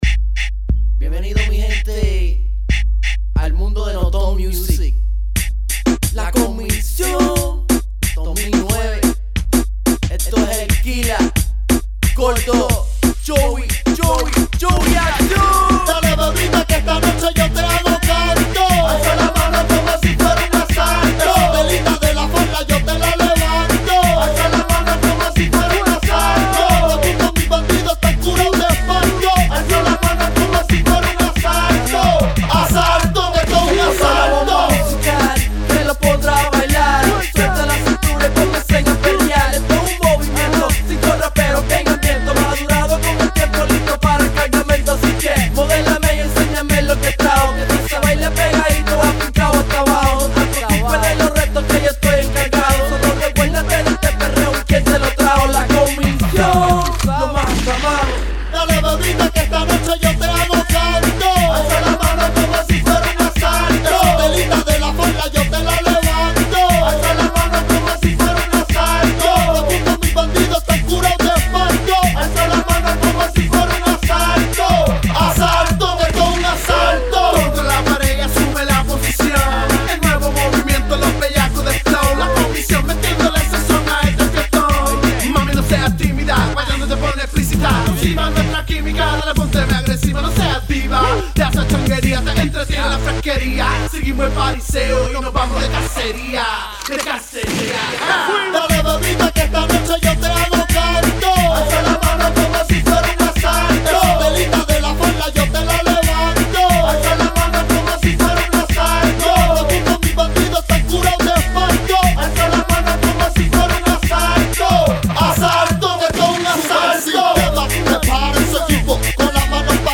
Reggaeton/ Hip Hop genre